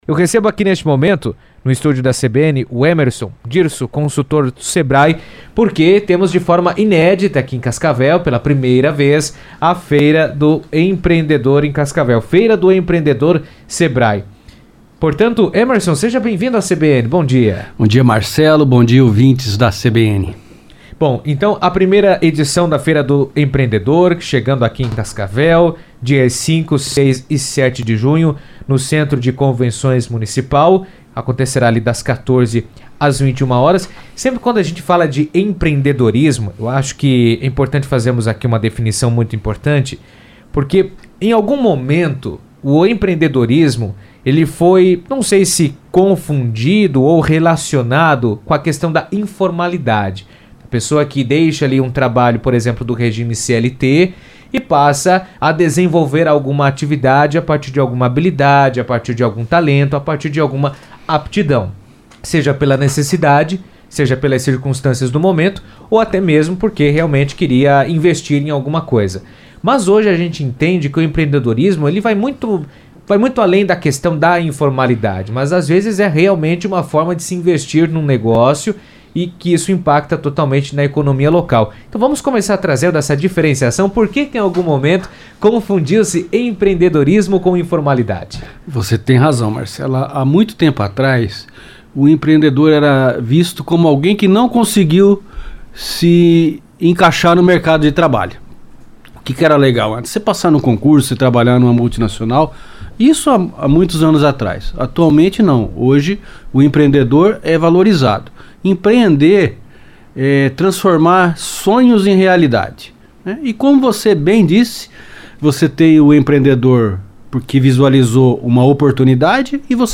esteve na CBN e trouxe detalhes da programação e a importância do empreendedorismo para a economia local.